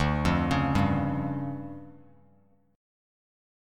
DmM7bb5 chord